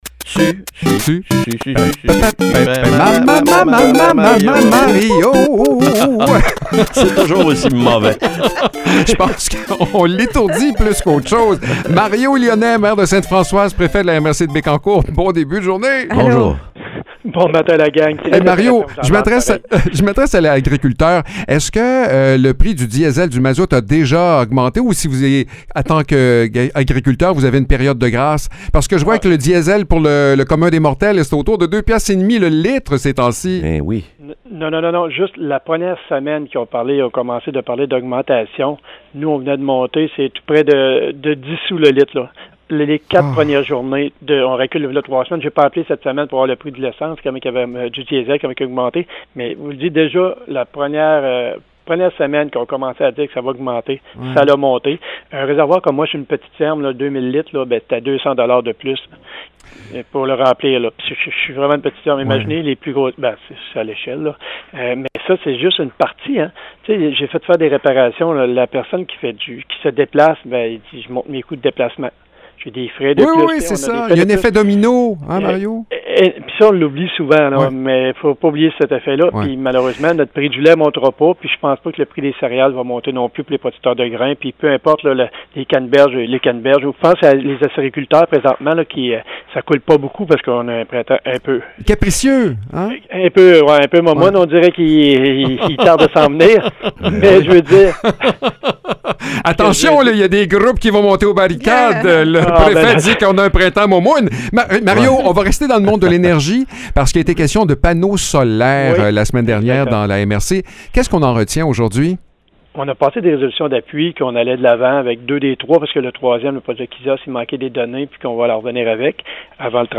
Mario Lyonnais, maire de Sainte-Françoise et préfet de la MRC de Bécancour, met son chapeau d’agriculteur pour nous parler de l’impact bien réel du prix du diesel sur les producteurs. On poursuit ensuite la discussion dans le monde de l’énergie avec un échange sur les panneaux solaires et les alternatives possibles.